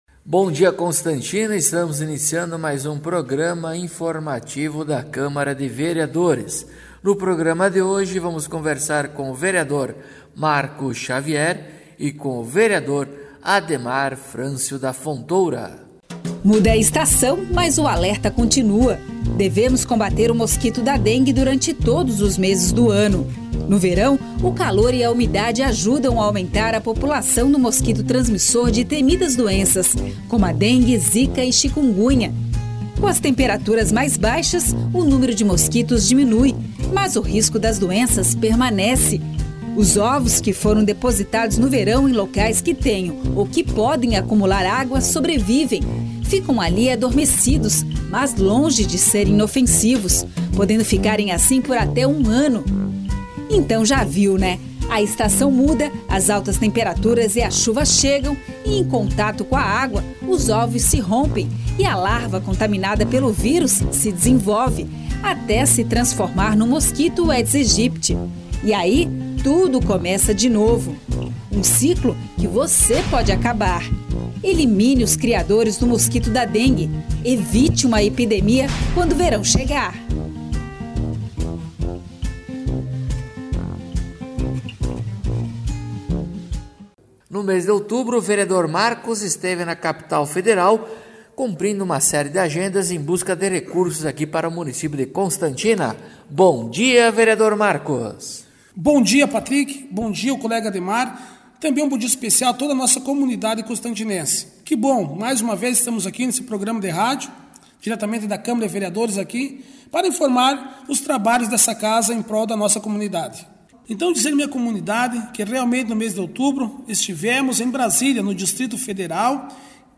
Acompanhe o programa informativo da câmara de vereadores de Constantina com o Vereador Marco Xavier e o Vereador Ademar Francio da Fontoura.